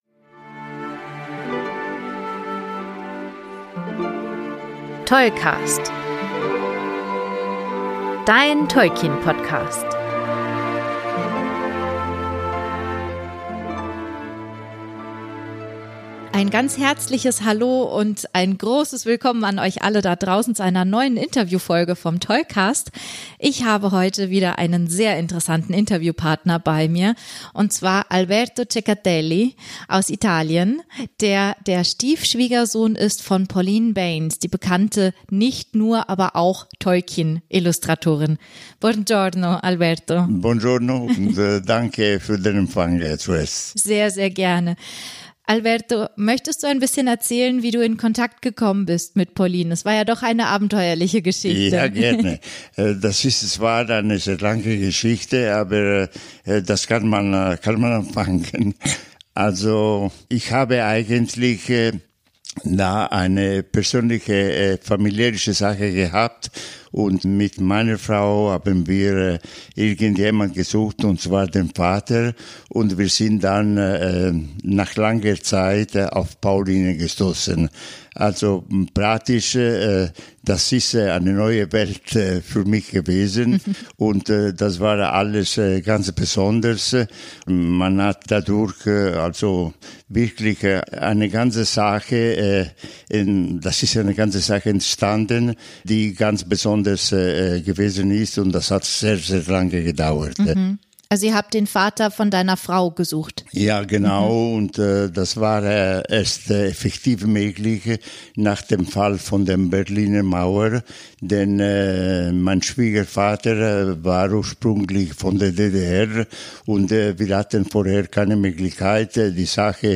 185 Interview